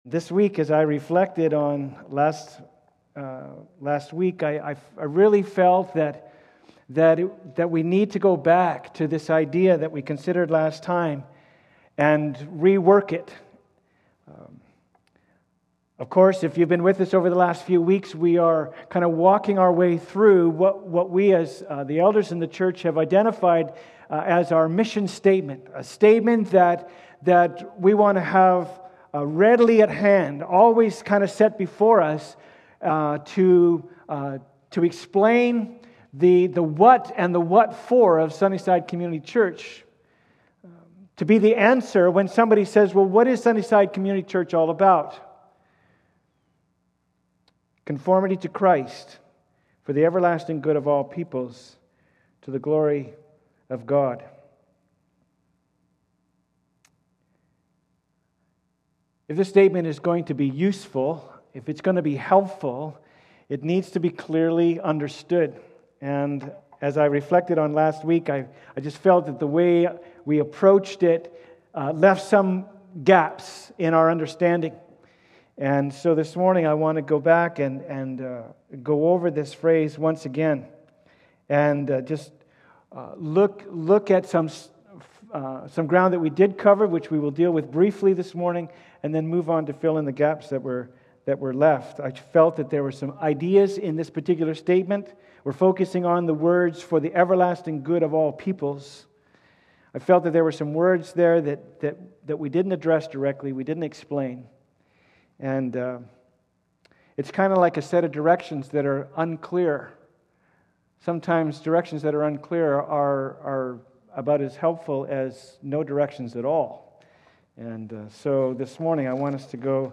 Service Type: Sunday Service
4.26_sermon.m4a